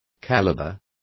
Complete with pronunciation of the translation of calibre.